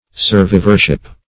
Survivorship \Sur*viv"or*ship\, n.